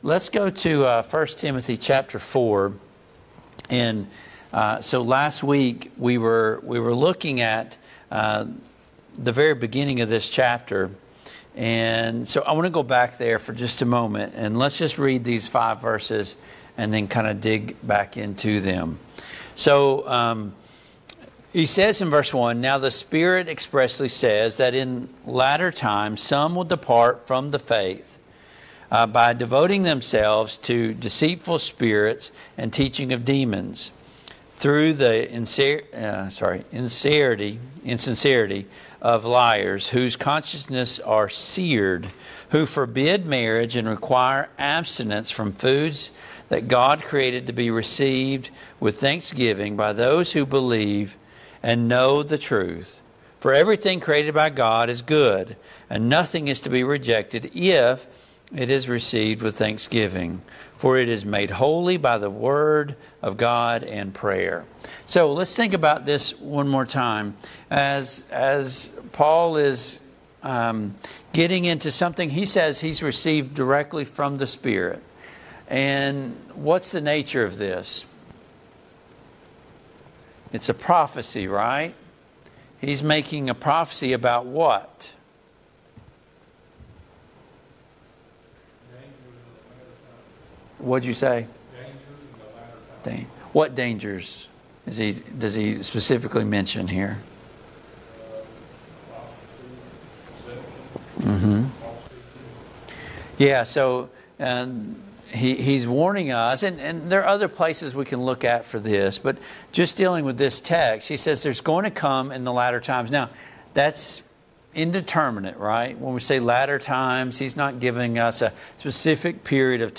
Passage: 1 Timothy 4:1-13 Service Type: Mid-Week Bible Study